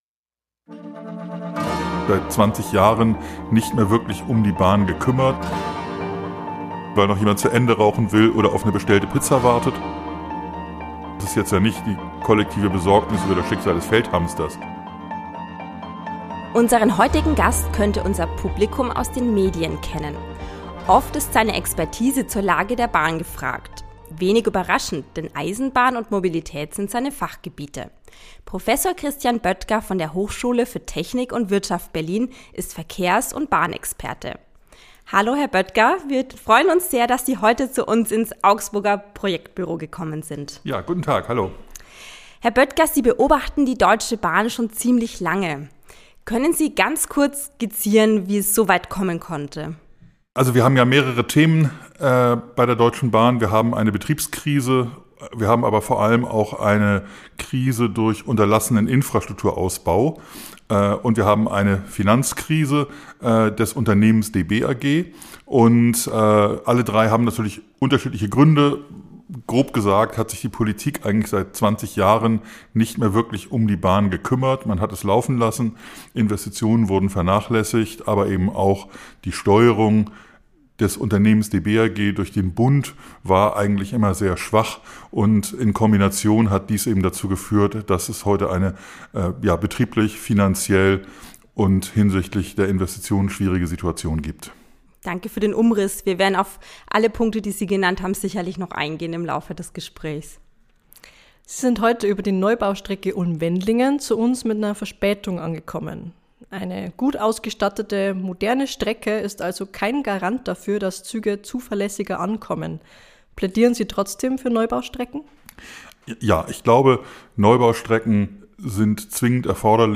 Wir haben mit ihm in unserem Büro in Augsburg gesprochen. Ein Gespräch über die Disziplin der Fahrgäste, die kollektive Sorge um den Feldhamster und verhängnisvollen Erfolg.